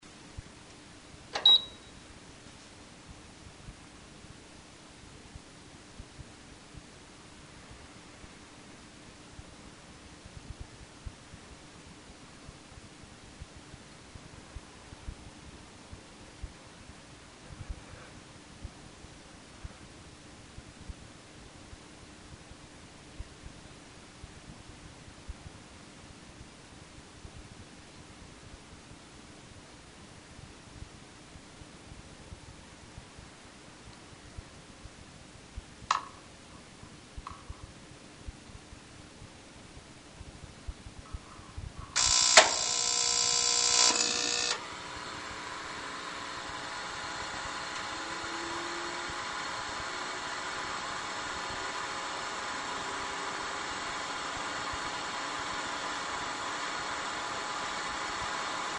FW-3301点火の音　350kB
dainichi-fw-3301-tenka.mp3